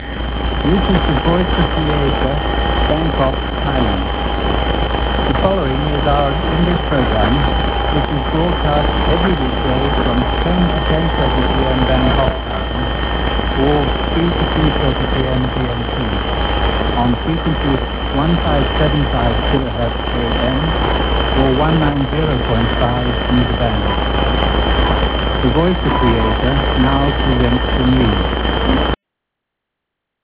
начало передачи на английском